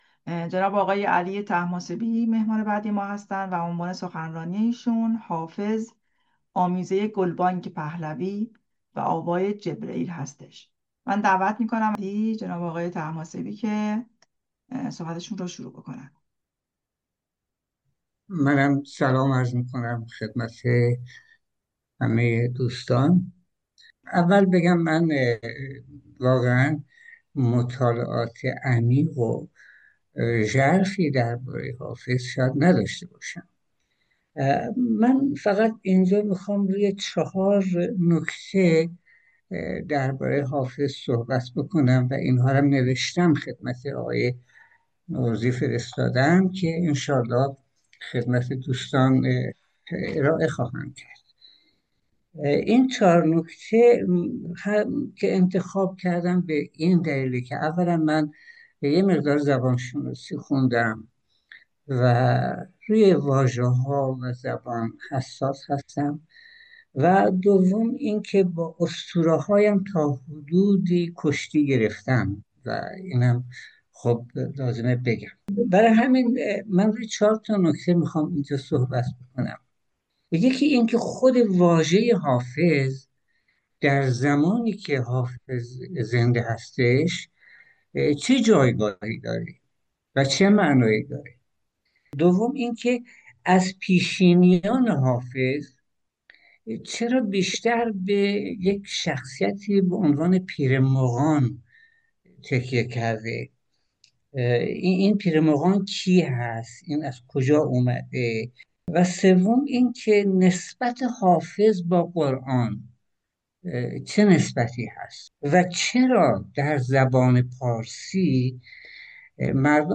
فایل صوتی سخنرانی: حافظ آمیزۀ گلبانگ پهلوی و آوای جبرئیل